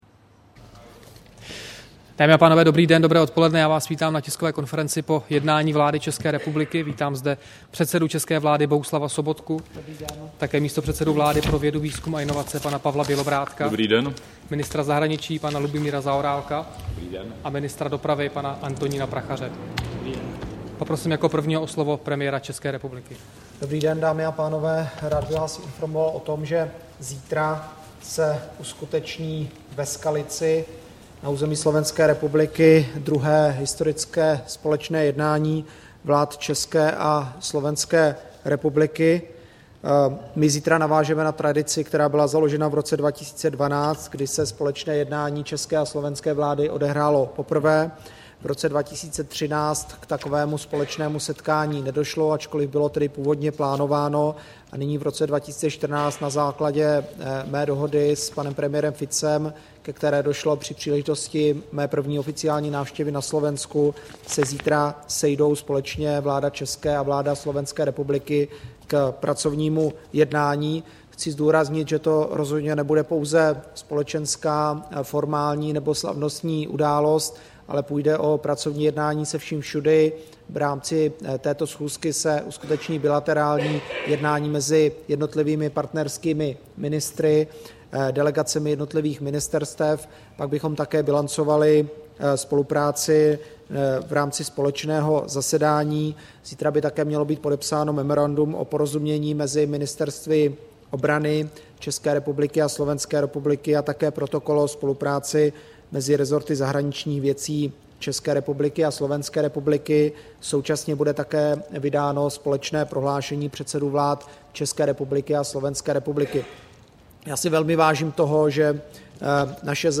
Tisková konference po jednání vlády, 23. dubna 2014